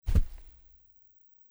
在积雪里行走的脚步声右声道－YS070525.mp3
通用动作/01人物/01移动状态/02雪地/在积雪里行走的脚步声右声道－YS070525.mp3
• 声道 立體聲 (2ch)